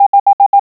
Click on a letter, number, or punctuation mark to hear it in Morse code.